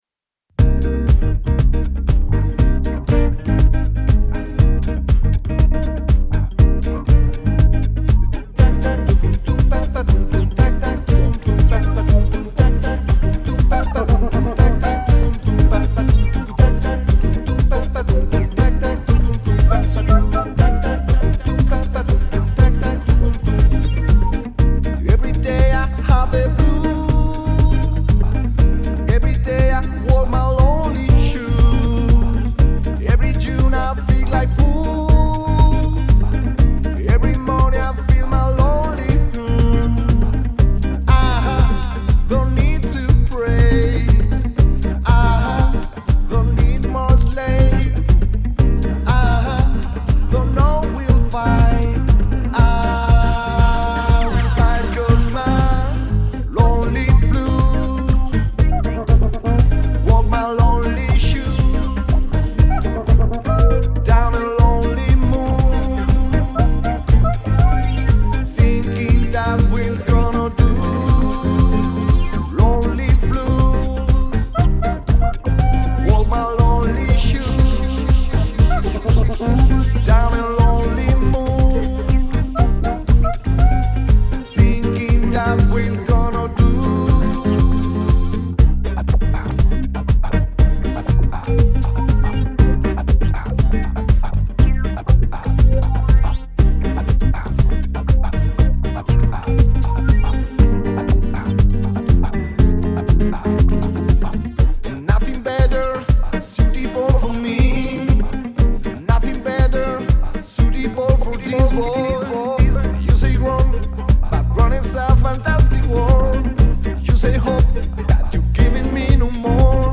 GRABADO EN 2017 EN EL LABORATORIO AUDIOVISUAL DE ZARAGOZA.